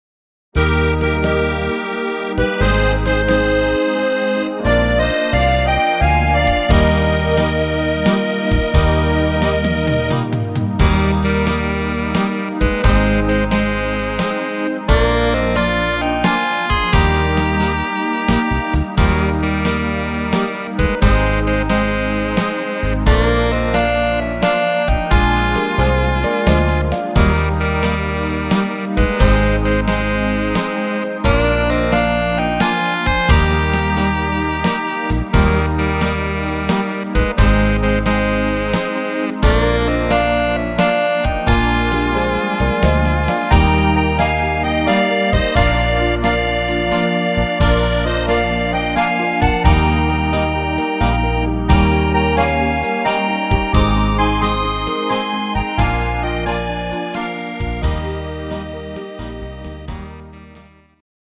instr. Klarinette